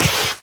biter-roar-2.ogg